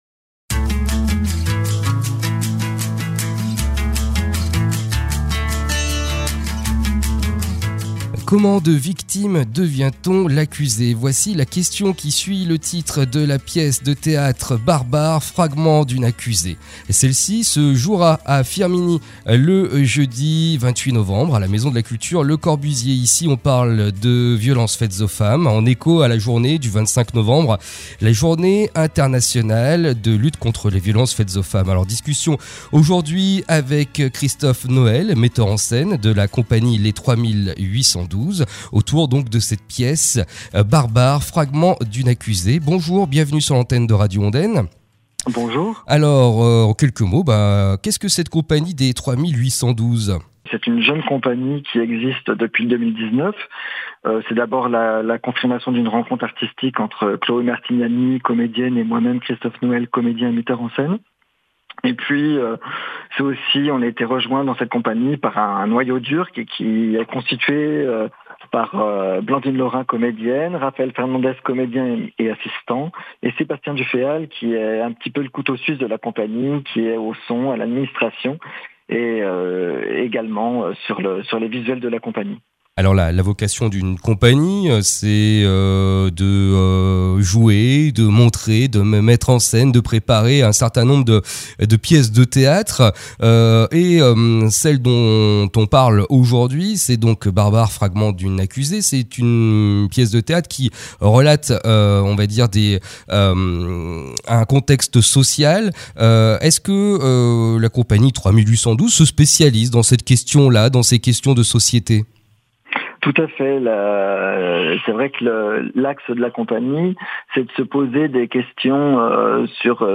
Interview à écouter aujourd’hui à 12h10 et 17h, mardi 26 à 13h et jeudi 28 à 11h30.